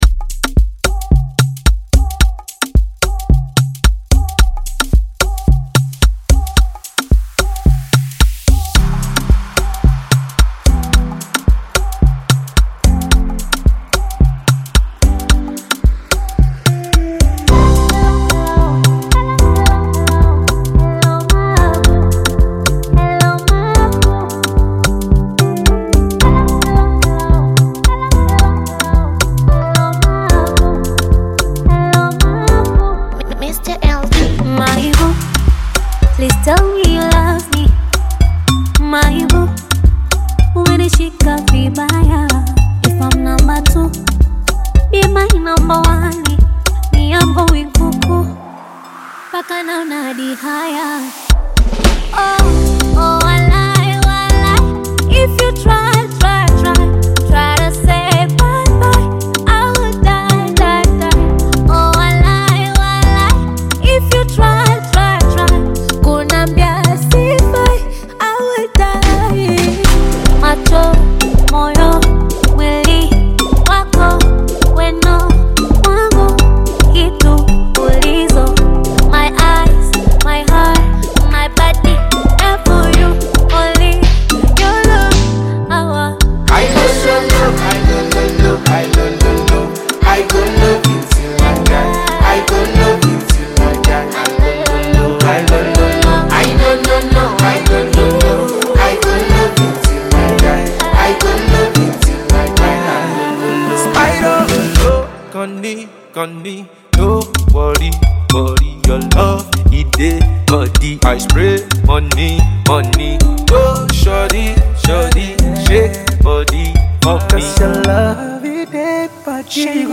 AfrobeatAudio
romantic Bongo Flava/Afro-Beat single